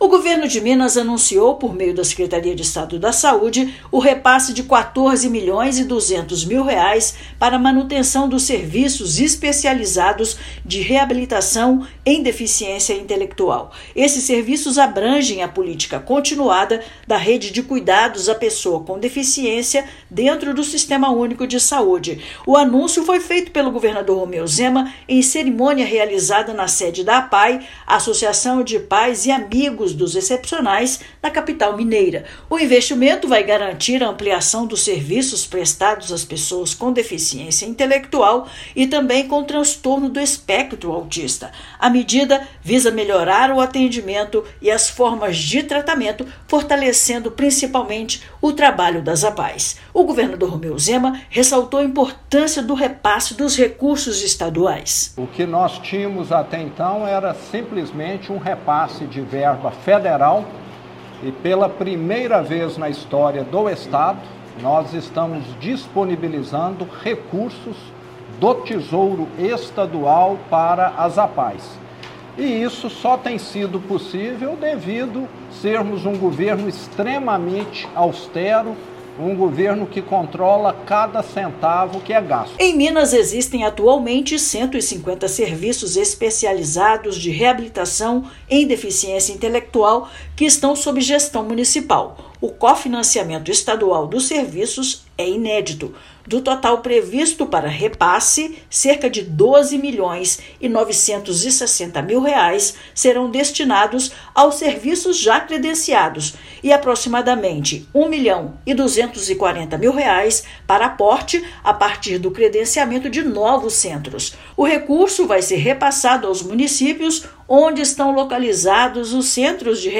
[RÁDIO] Governo de Minas investe mais de R$ 14 mi em serviços para pessoas com deficiência intelectual e transtorno do espectro autista
Novo investimento vai ampliar a rede de cuidados à pessoa com deficiência e melhorar as formas de tratamento oferecidas em Minas Gerais. Ouça matéria de rádio.